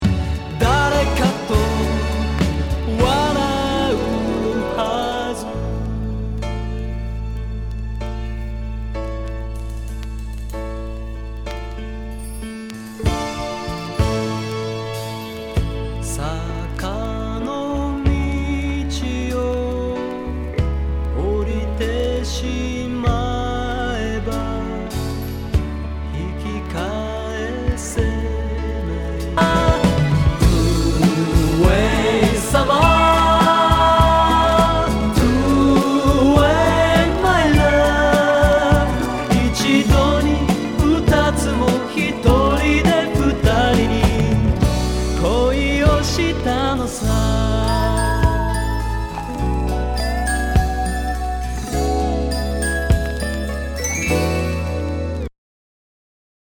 [ Genre ] 和モノ/JAPANEASE GROOVE
ナイス！シティポップ・ライトメロウ！
全体にチリノイズが入ります